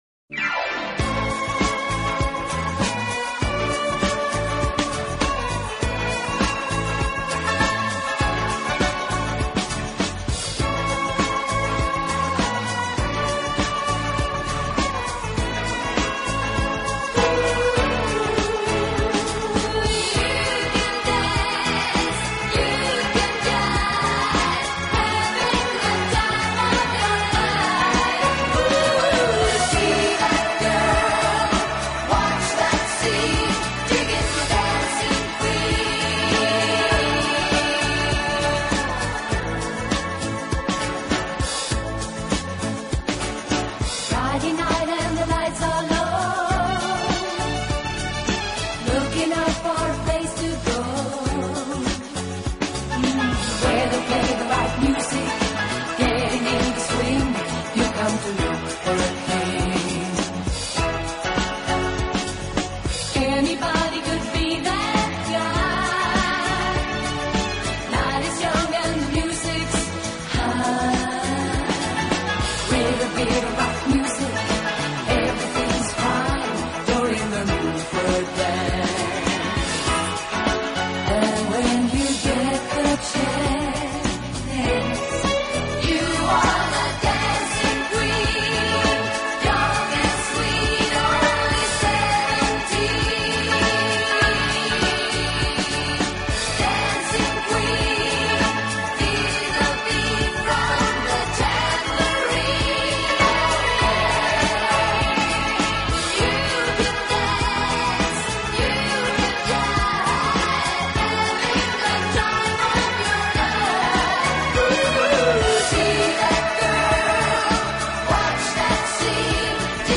音乐风格：流行|流行/摇滚|(Pop/Rock)